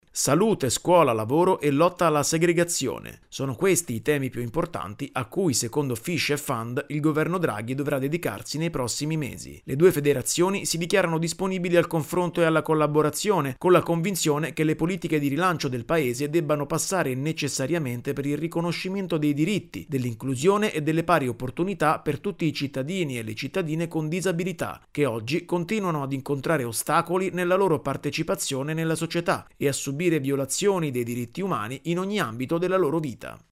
Interventi strutturali a sostegno della disabilità: le proposte di Fish e Fand al governo Draghi nel servizio